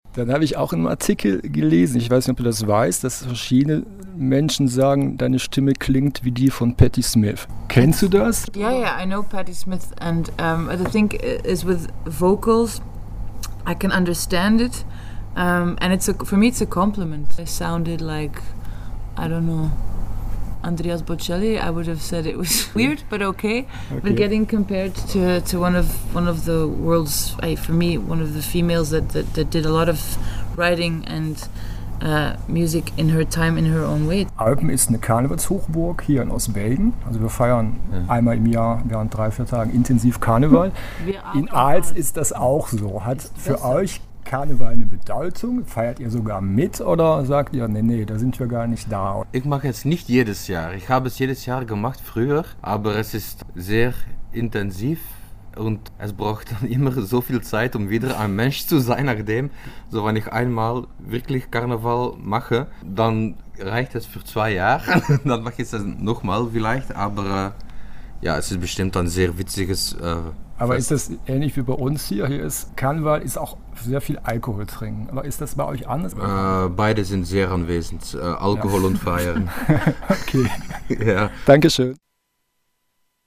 Band-Talk mit den Intergalactic Lovers
Die Intergalactic Lovers haben am Mittwochabend das Kulturzentrum Alter Schlachthof in Eupen gerockt!